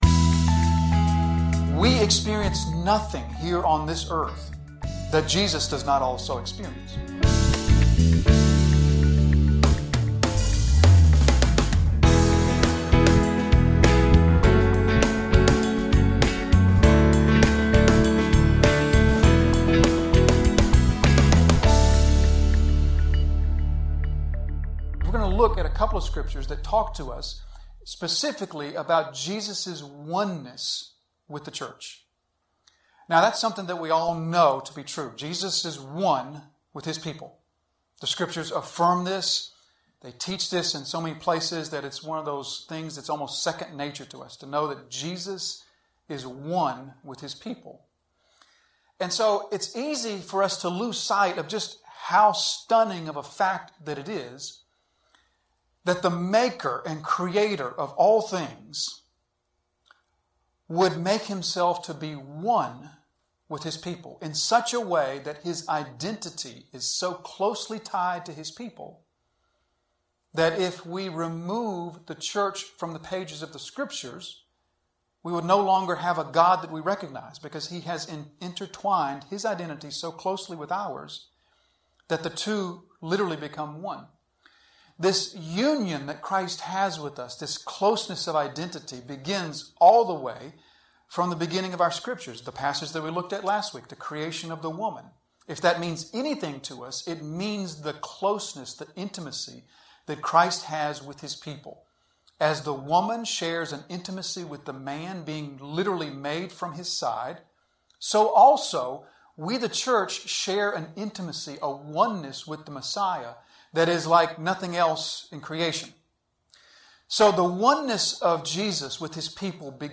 An expository sermon delivered at Disciples Fellowship Church, Jonesville, NC.